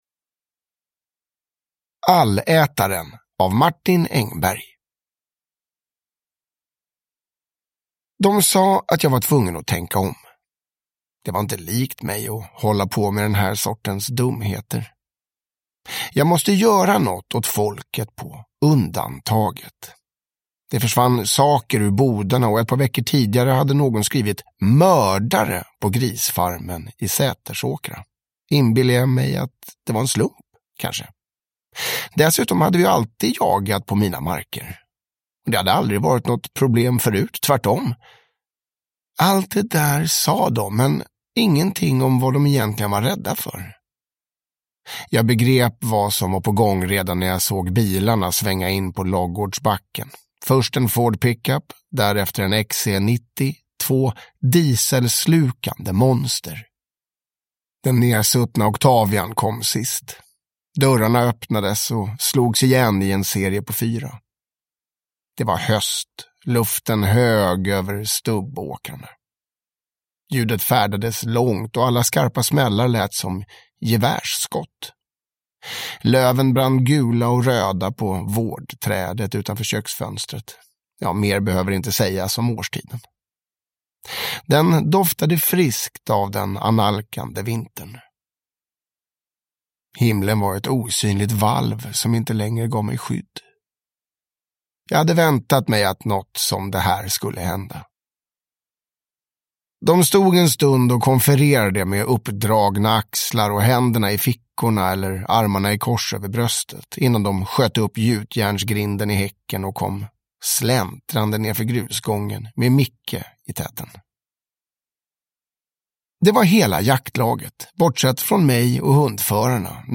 Allätaren – Ljudbok